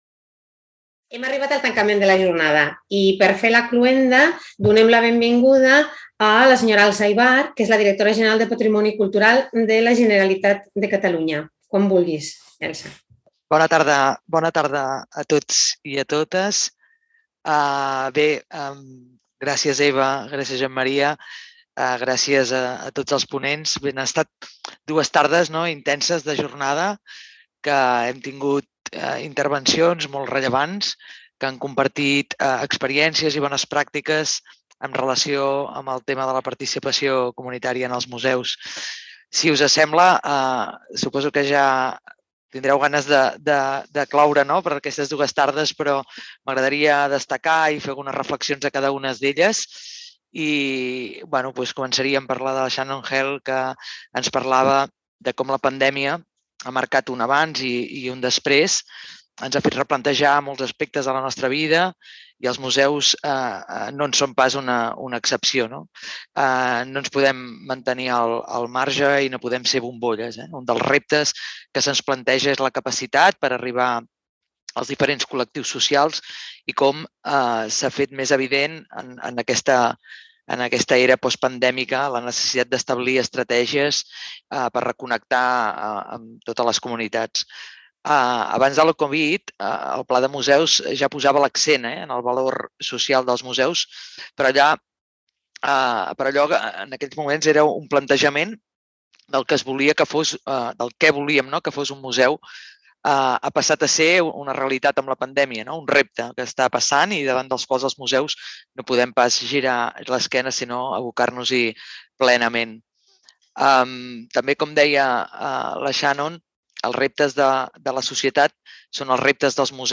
Acte de cloenda de la 6a Jornada Observatori dels Públics del Patrimoni Cultural de Catalunya a càrrec d'Elsa Ibars, directora general del patrimoni cultura